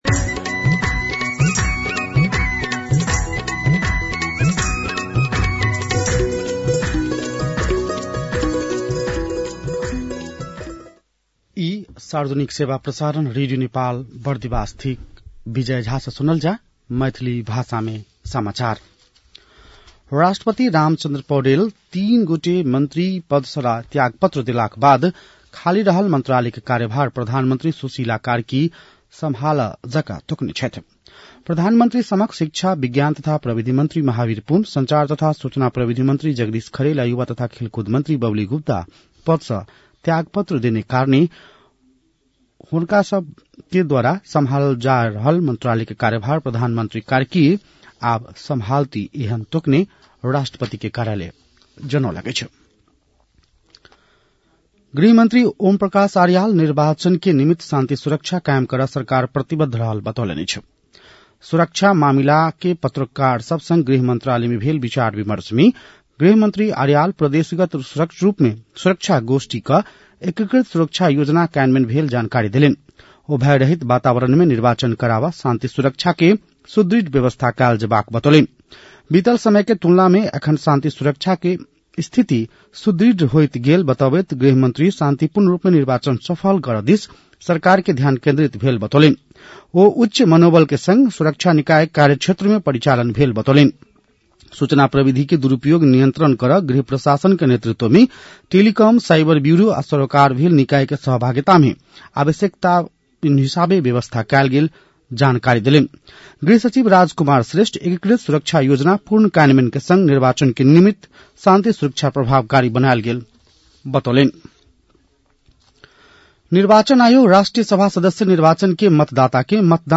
मैथिली भाषामा समाचार : ६ माघ , २०८२